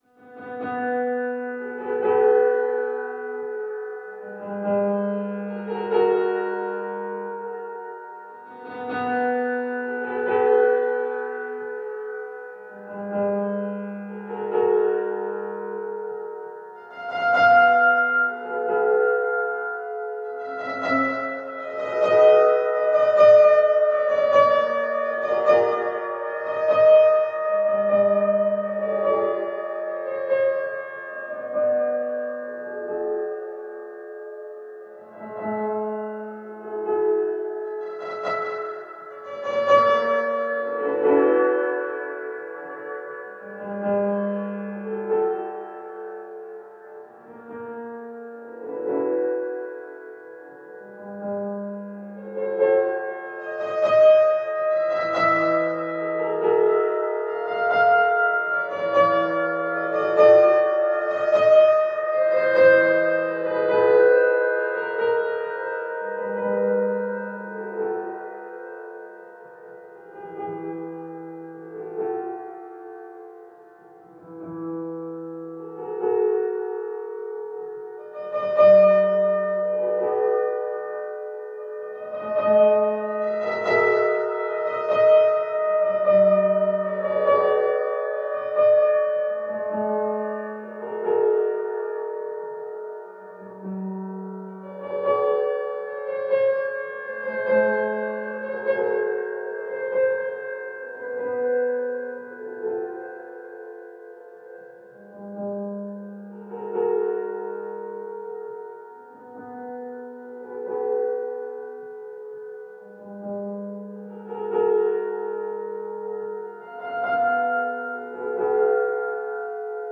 Les outils de synthèse, d’échantillonnage, de traitement Native Instruments ne sont plus à présenter mais je me délecte toujours autant du piano jouable en quarts de ton ainsi que de la synthèse et des traitements granulaires.
Erik Satie joué en piano quarts de ton et traitement sonore.
Erik-Satie-en-quarts-de-ton-FX.wav